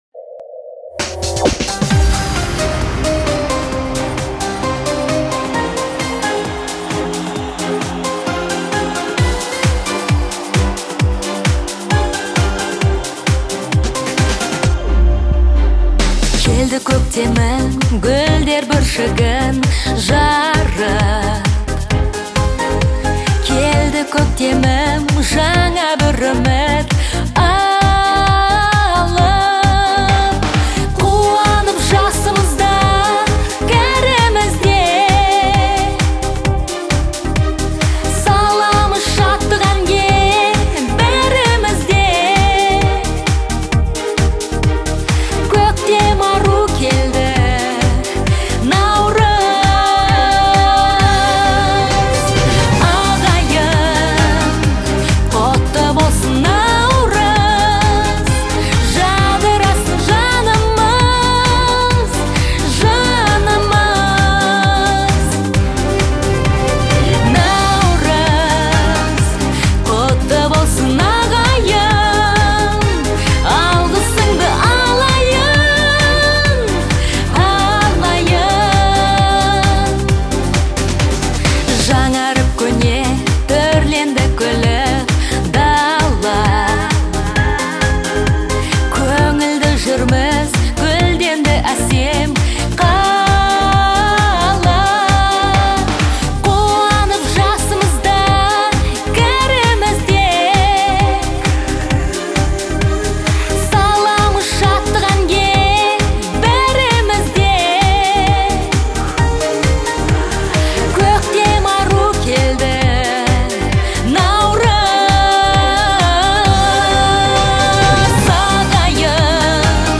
это яркая и мелодичная песня в жанре казахской поп-музыки